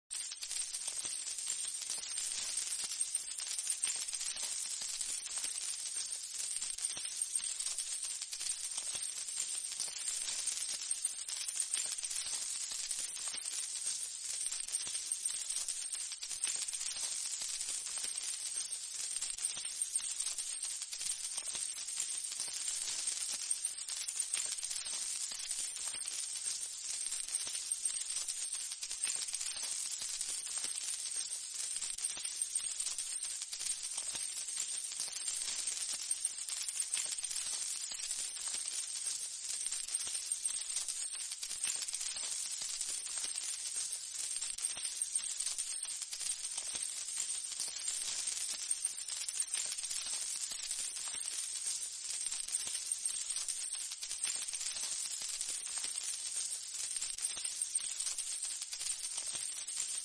A large amount of money sound effects free download
A large amount of money (coins and bills) falls out of the sack — AI-generated